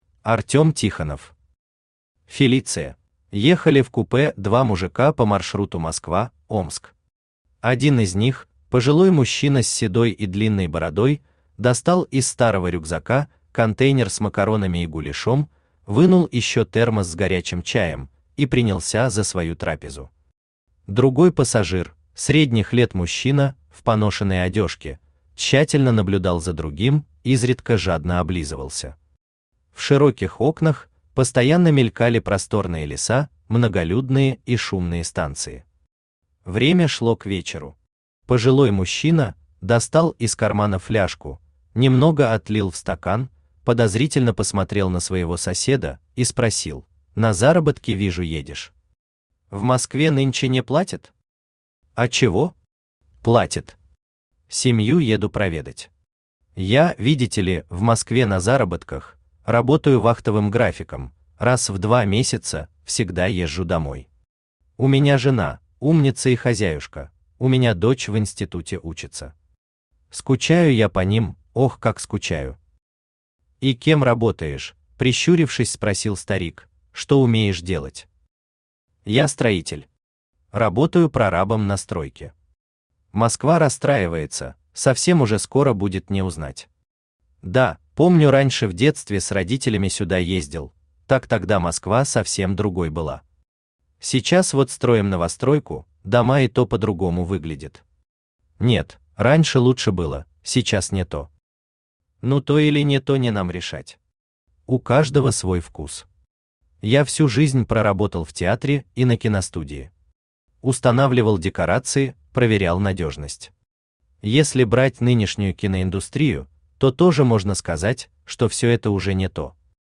Aудиокнига Фелиция Автор Артем Алексеевич Тихонов Читает аудиокнигу Авточтец ЛитРес.